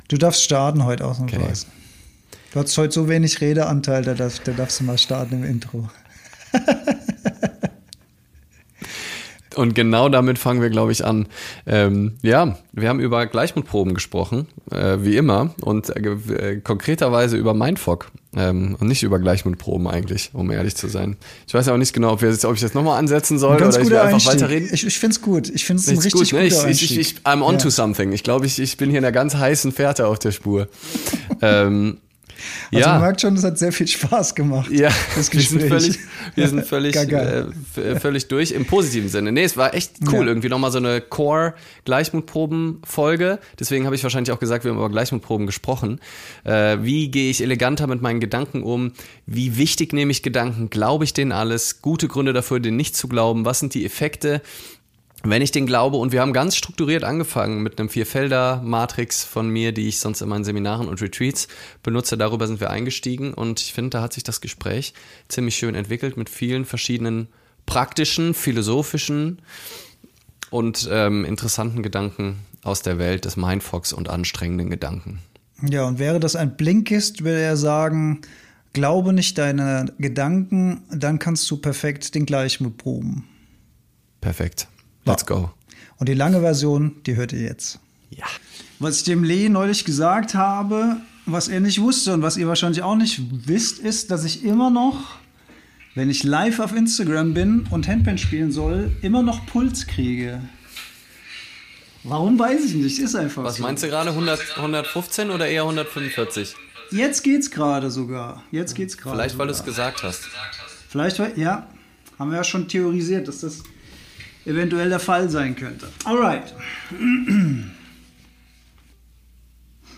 In diesem wunderschönen Live haben wir über Mindfog gesprochen und festgestellt, dass es zeitgleich das Wichtigste und unwichtigste Thema aller Zeiten ist.